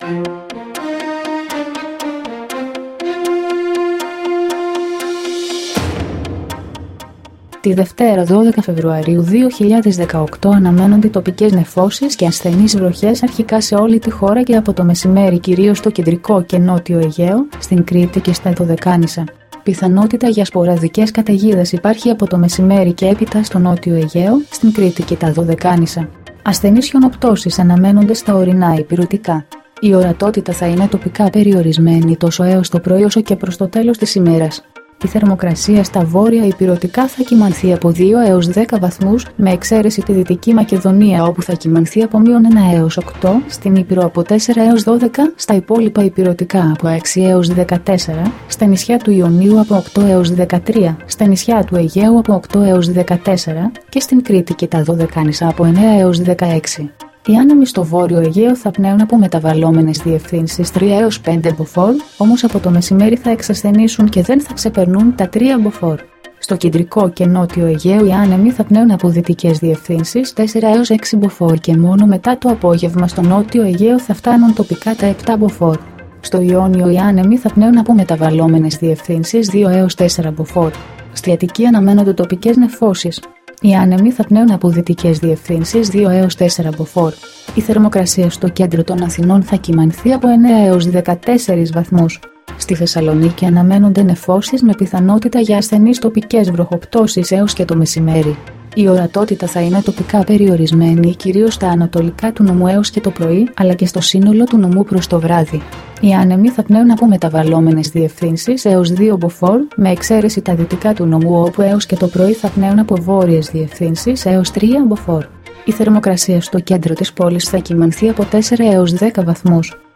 dailyforecast-100.mp3